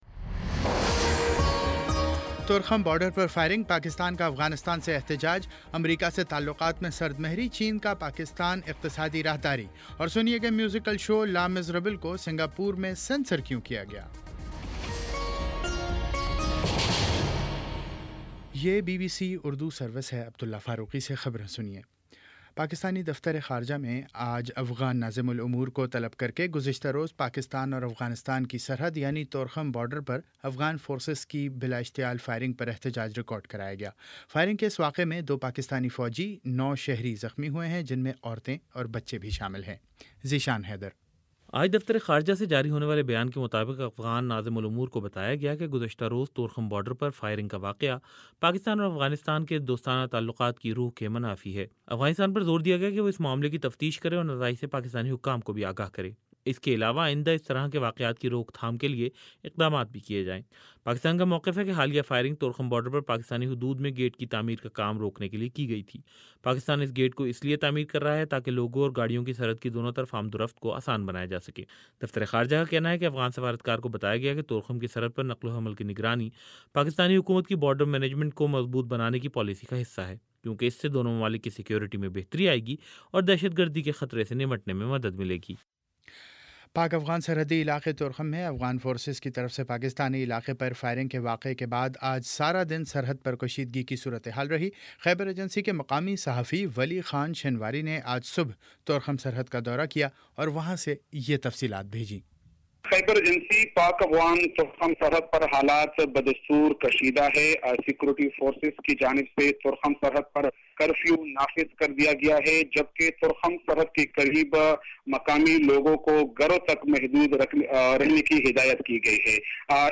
جون 13 : شام پانچ بجے کا نیوز بُلیٹن